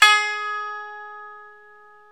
ETH XKOTO 05.wav